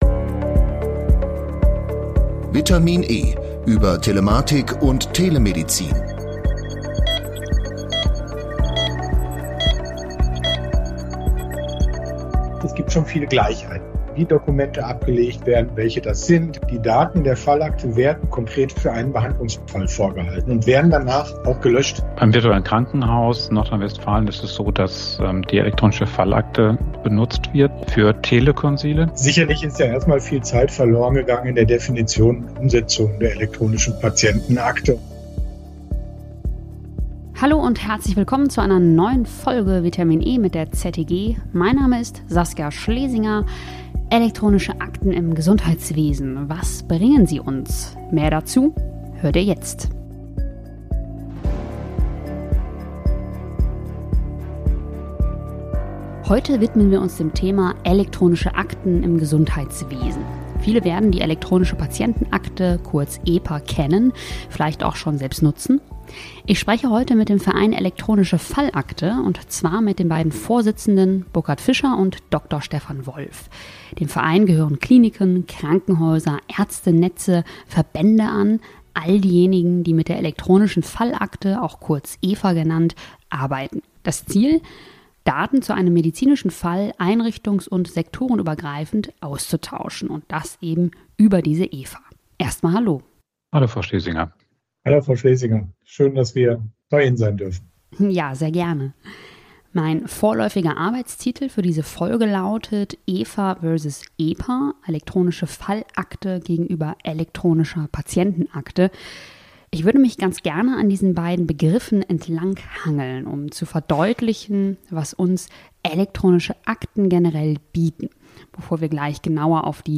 Die Fragen haben wir daher mit in unser Gespräch genommen.